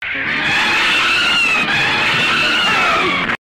Zombieron_roar.mp3